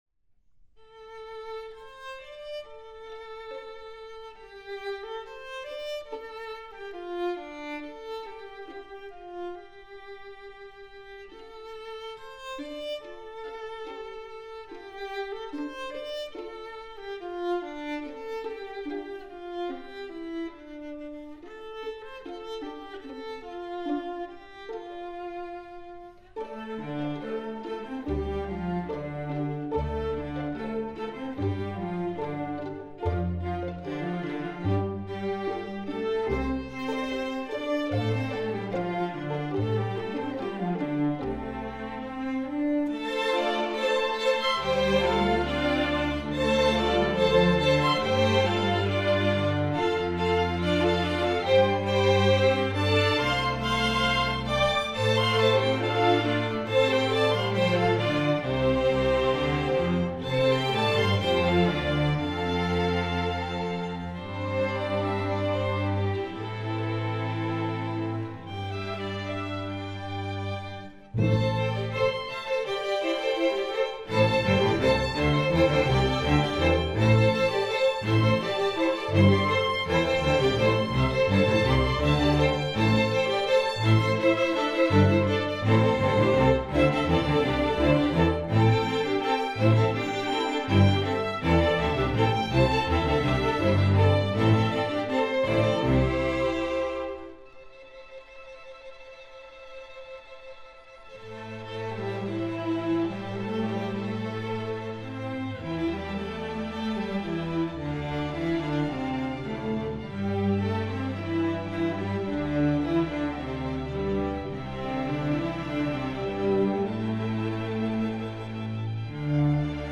Instrumentation: string orchestra
instructional